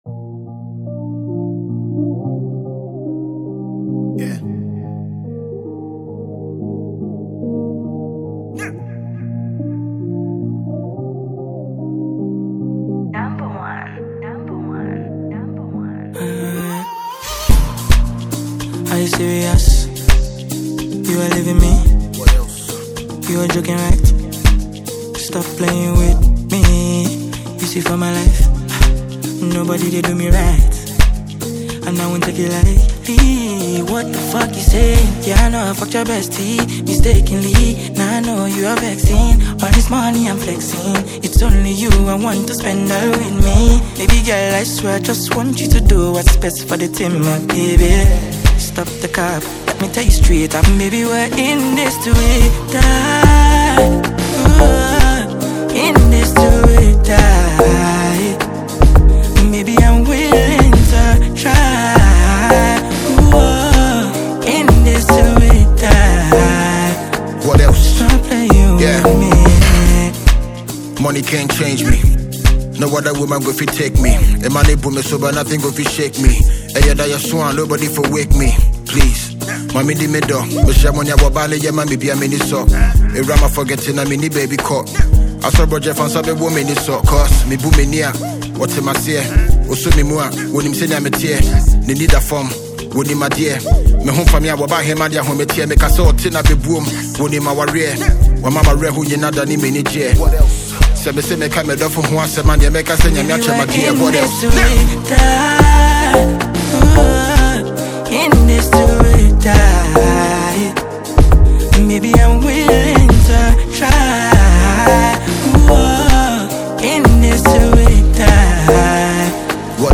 Known for his versatile rap style